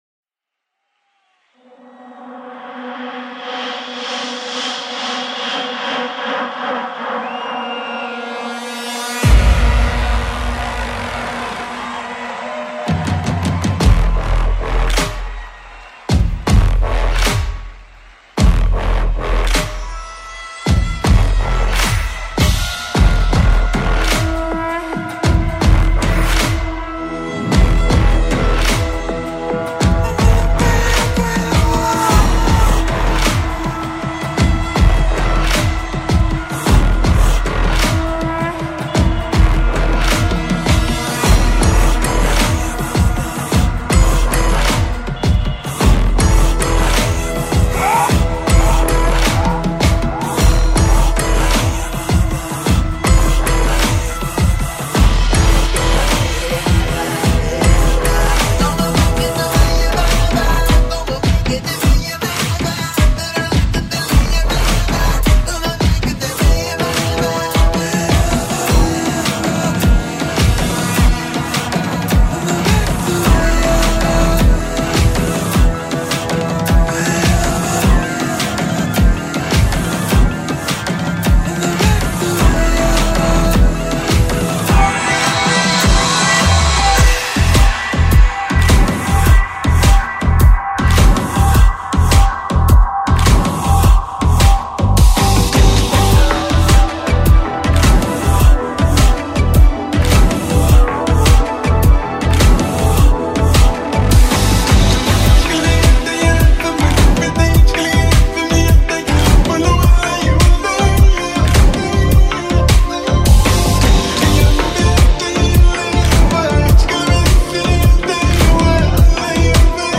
ریمیکس ارکستی بندی همراه با نی انبان
ریمیکس شاد ارکستی
ریمیکس شاد و بندری مخصوص رقص